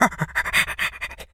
monkey_chatter_05.wav